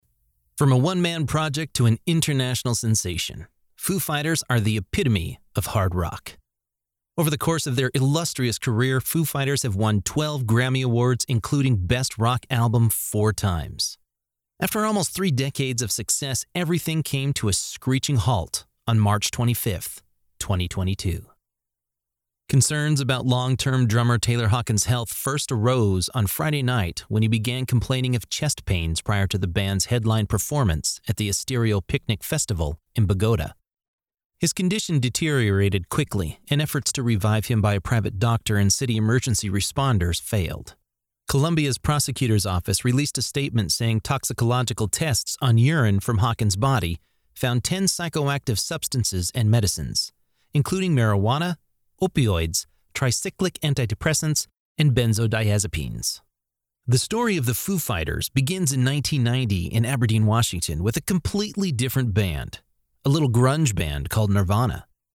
A professional voice actor with a warm, trustworthy, and versatile sound.
Documentary